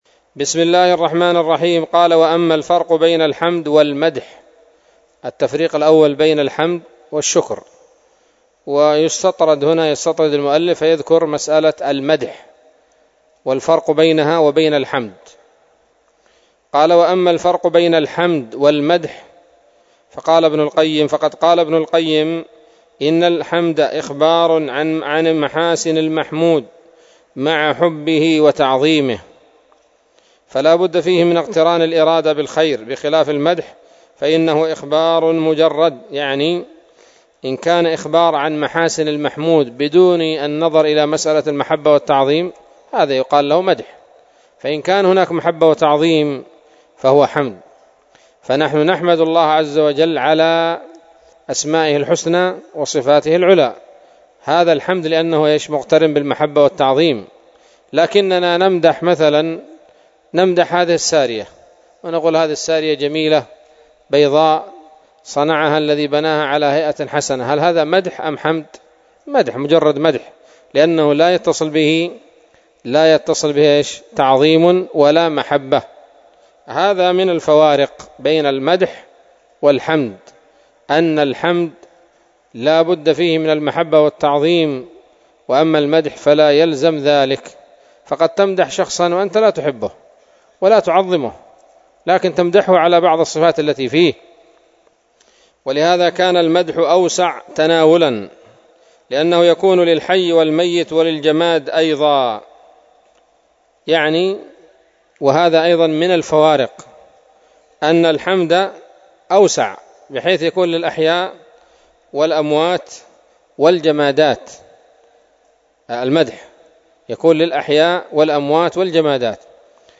الدرس التاسع من شرح العقيدة الواسطية للهراس